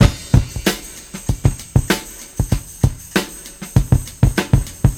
• 97 Bpm Drum Groove A# Key.wav
Free breakbeat sample - kick tuned to the A# note. Loudest frequency: 1131Hz
97-bpm-drum-groove-a-sharp-key-jn3.wav